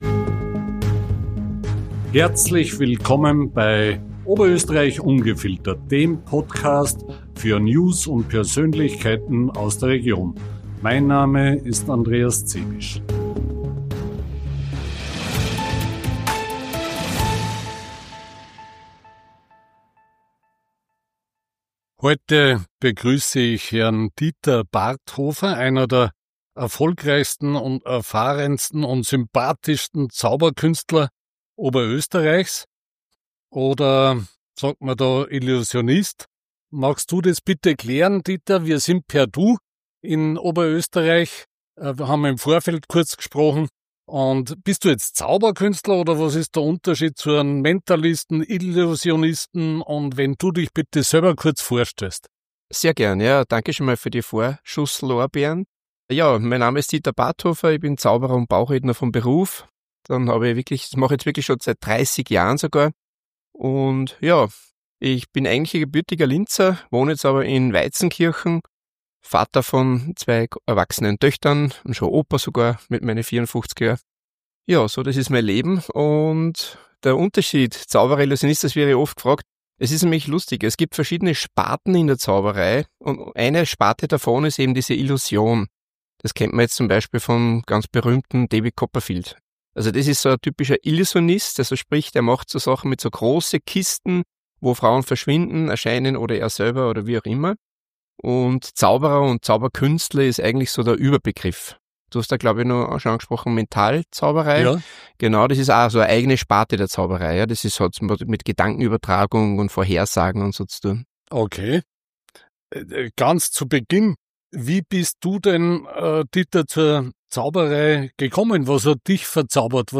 Das Gespräch gibt faszinierende Einblicke in die Welt der Zaubere...